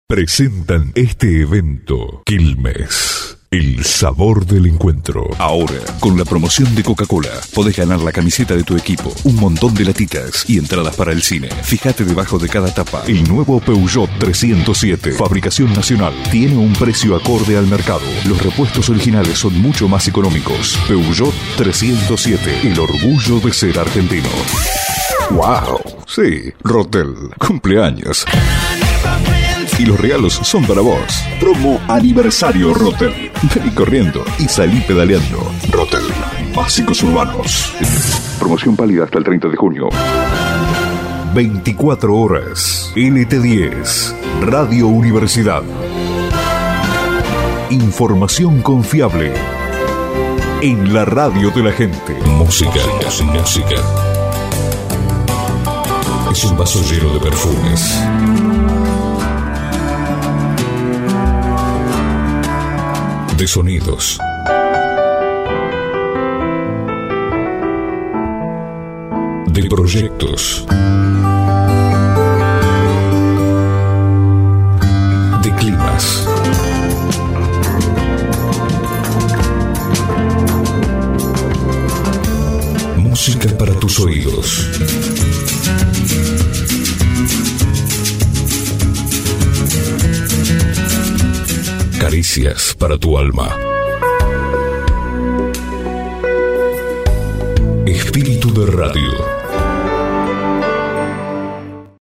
voice over
Demo-publicidad.mp3